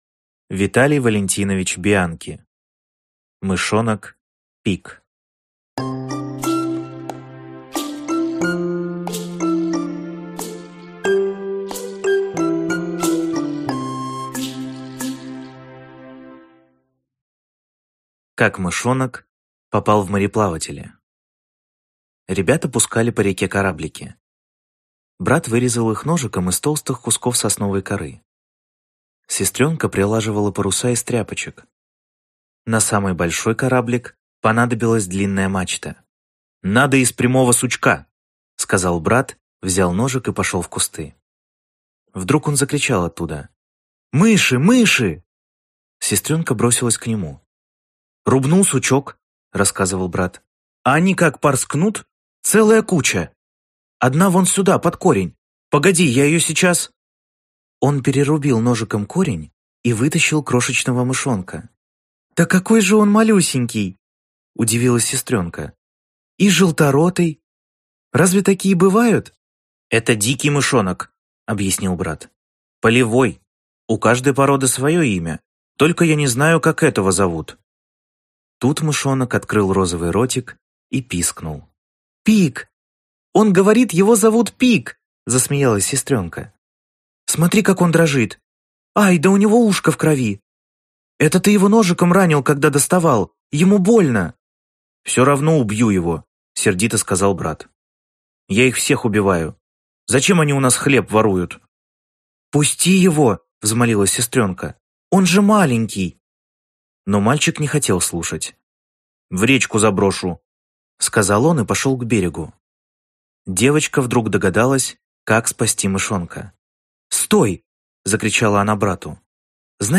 Аудиокнига Мышонок Пик | Библиотека аудиокниг